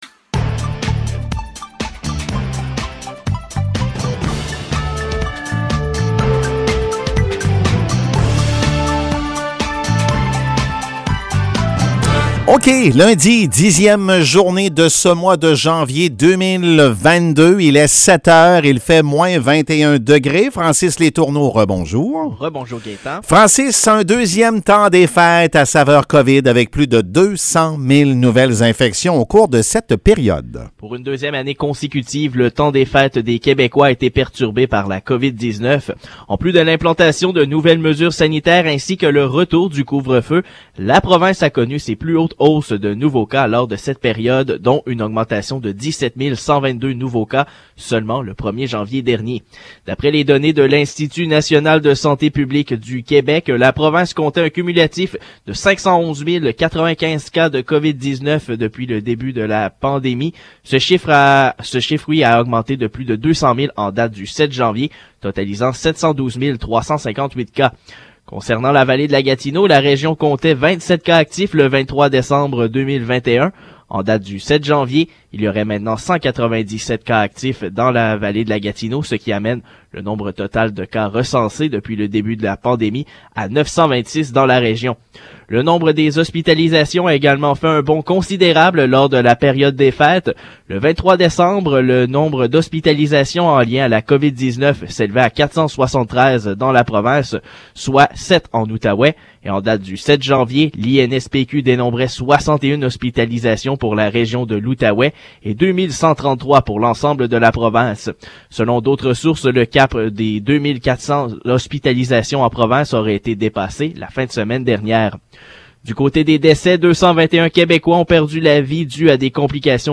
Nouvelles locales - 10 janvier 2022 - 7 h